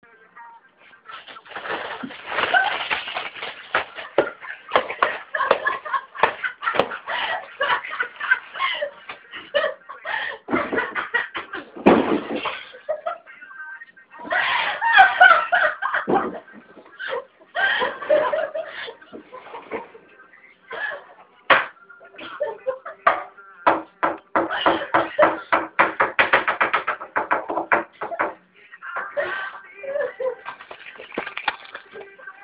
Field Recording #1
SOUNDFILE Dorm Room.
Sounds heard: laughter, music, rustling, coughing, hammering